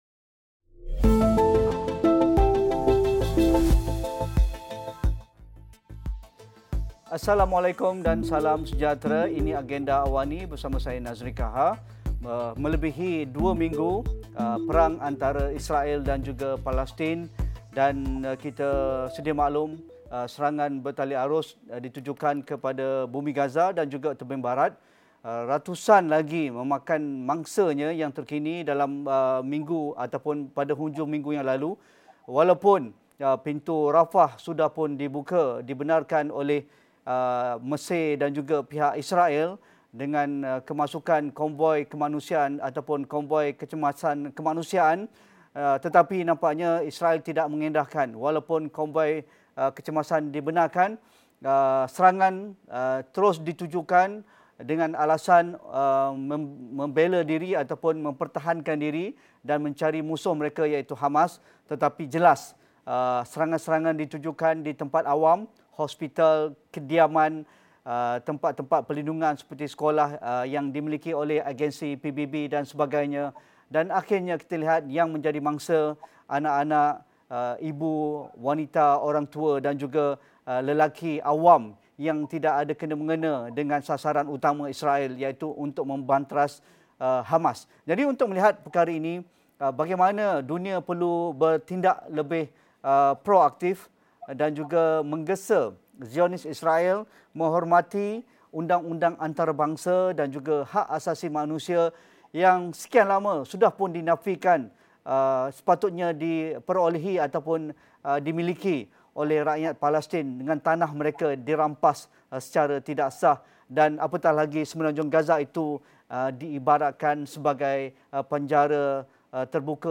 Mengapa dunia perlu tegas mendesak gencatan senjata perang Israel-Palestin untuk beri lebih banyak laluan kepada misi bantuan kecemasan kemanusiaan, selain elak lebih banyak nyawa penduduk terkorban? Diskusi 8.30 malam